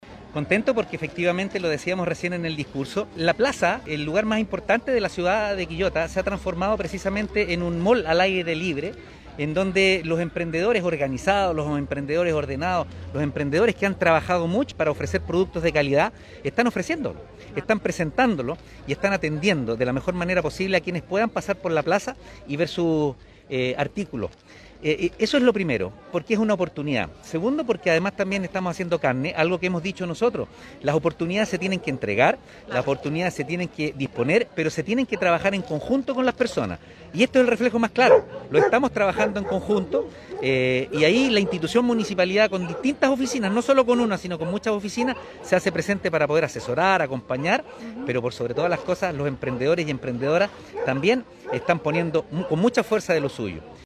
Alcalde-Oscar-Calderon-Sanchez-1-2.mp3